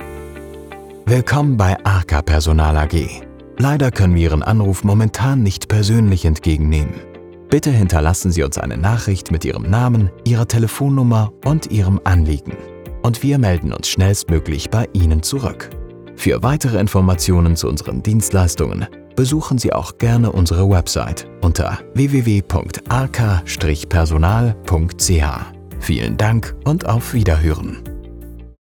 Natural, Reliable, Friendly, Commercial, Warm
Telephony